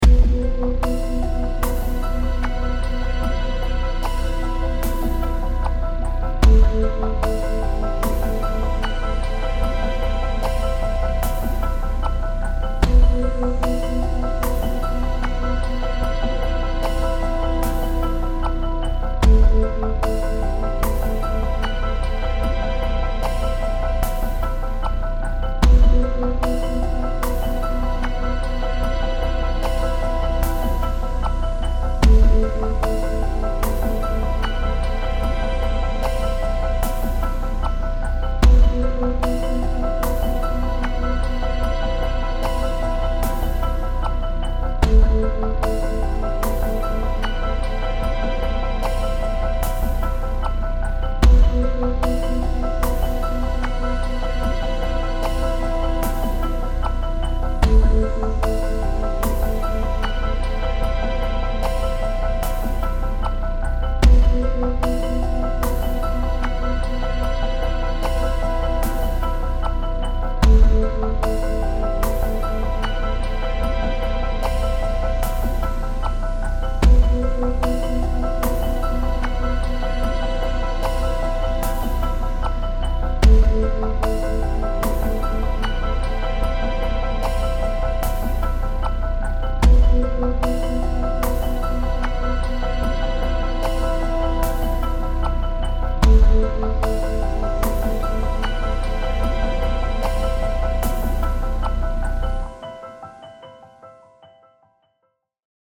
少しだけリズム等が追加されております。